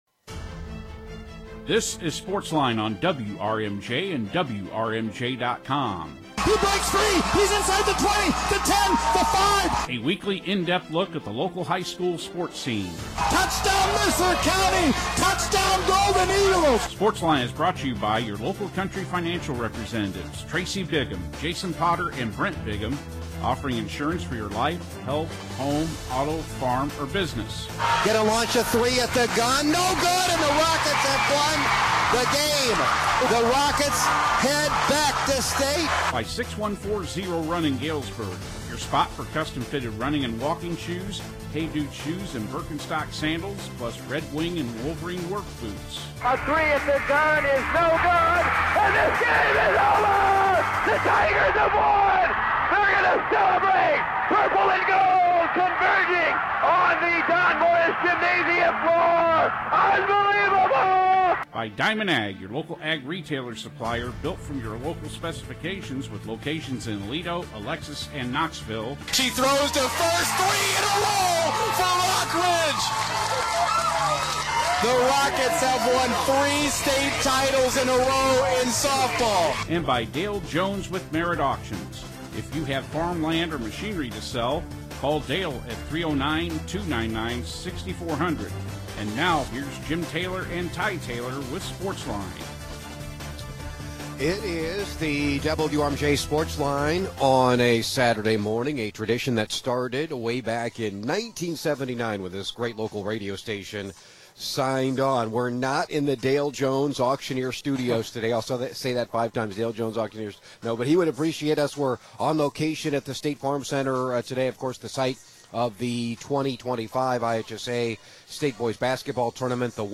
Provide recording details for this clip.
WRMJ Sports - WRMJ Sportsline: Live from IHSA Boys Basketball State Finals